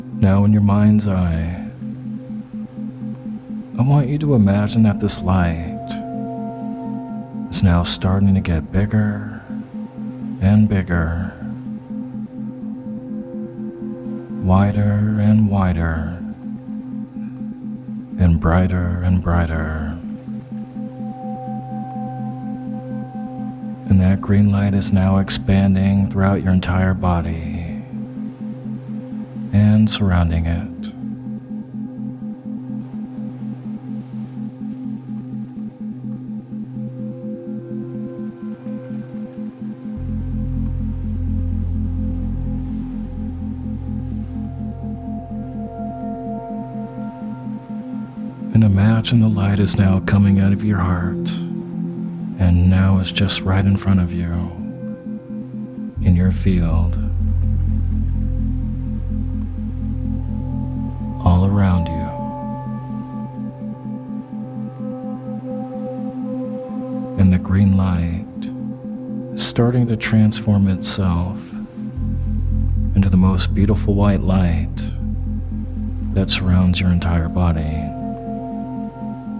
Sleep Hypnosis for Opening the Heart Chakra With Isochronic Tones
This Sleep hypnosis for created to help open the heart chakra and to cultivate gratitude and compassion. **Includes Isochronic Tones